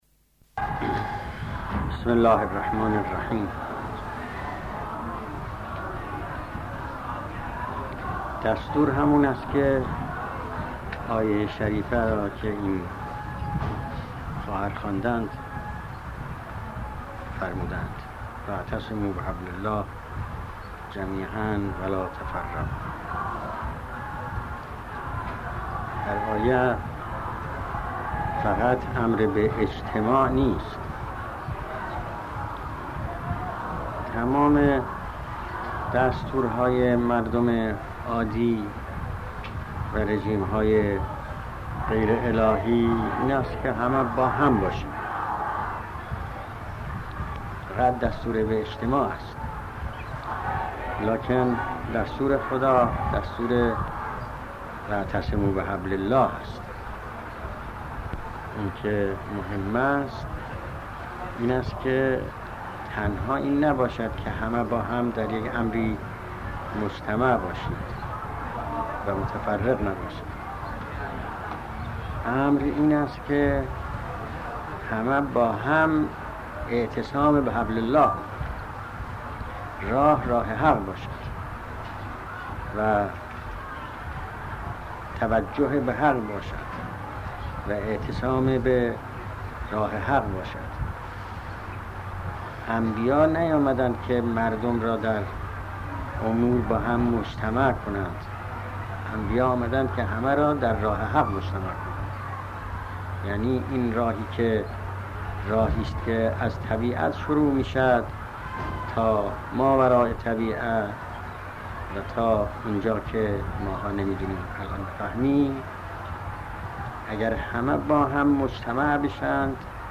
سخنرانى در جمع بانوان دانشجوى اصفهان (رمز پيروزى، وحدت و اسلامخواهى)